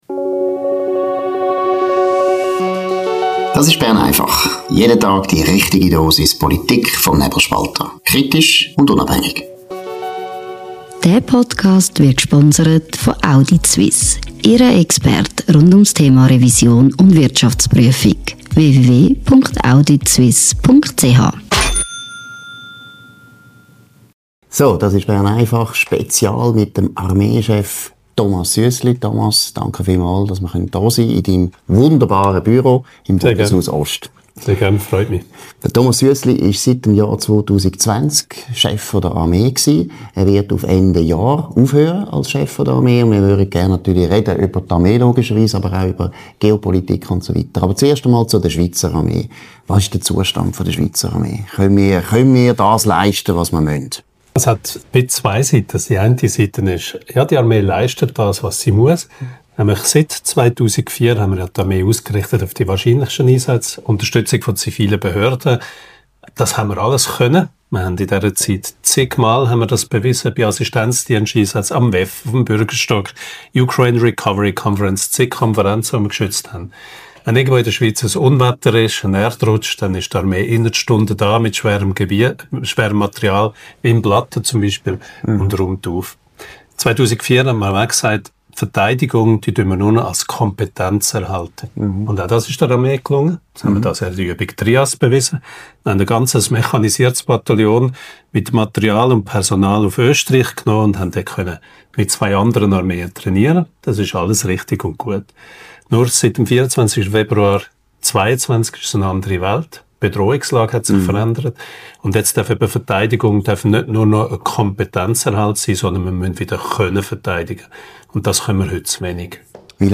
Im exklusiven Interview
zum Gespräch im Bundeshaus Ost